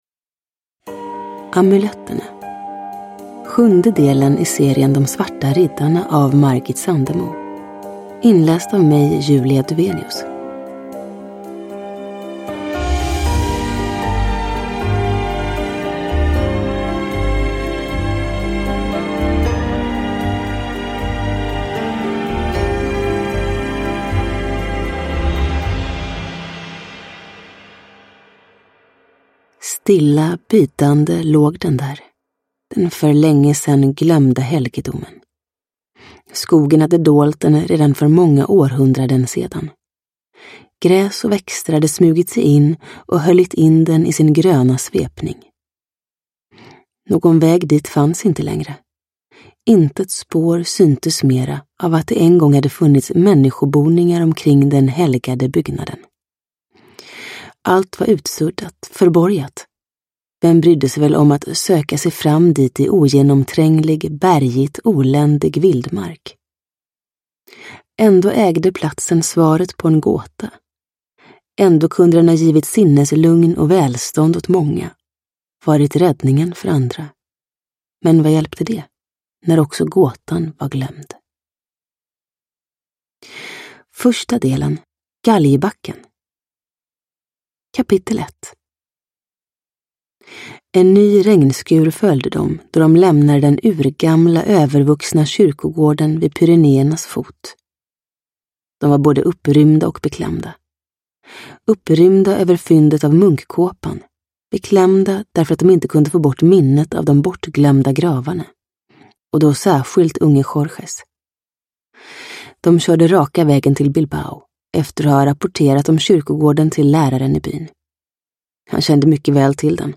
Uppläsare: Julia Dufvenius